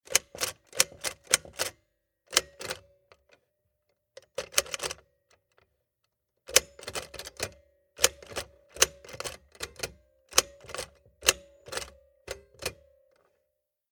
Kinder-Schreibmaschine Bambino
Schreibhebel auslösen
0035_Schreibhebel_ausloesen.mp3